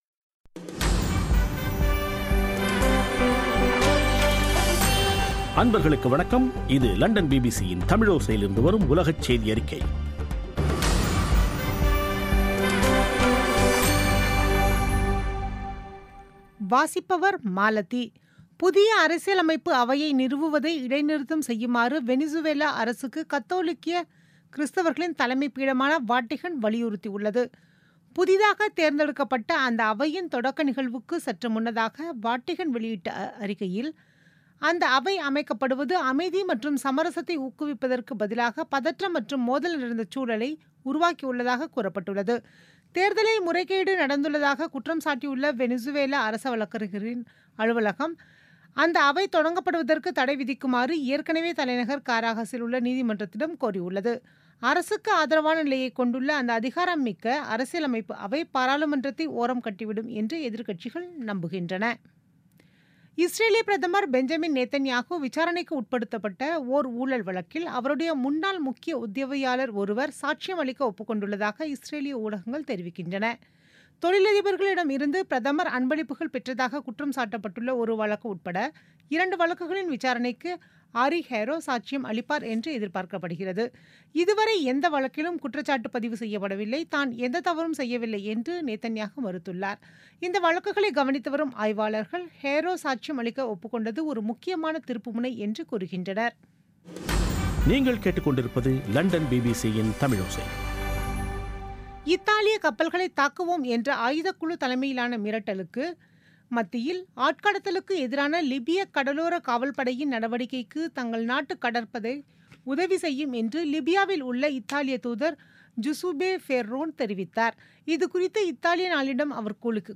பிபிசி தமிழோசை செய்தியறிக்கை (04/08/2017)